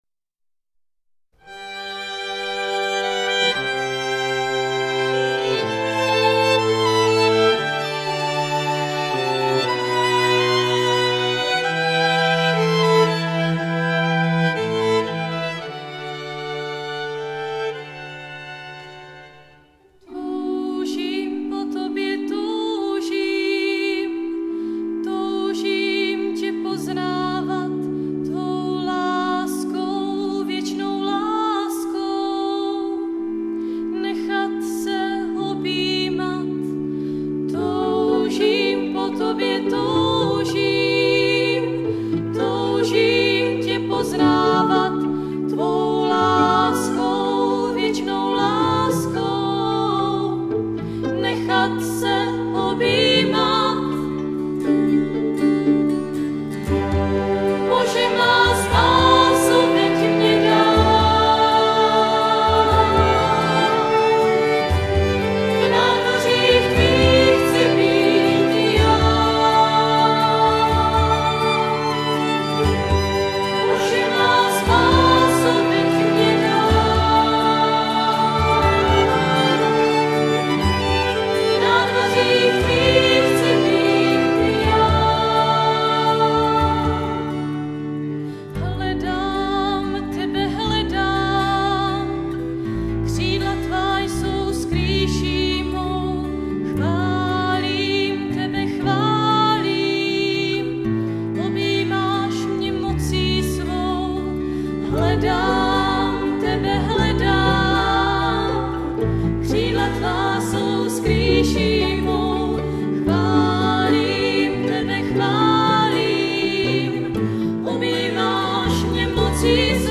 Nahráno 24.-26. června 2003 v modlitebně CČSH v Kroměříži.
Klíčová slova: písně, schóla,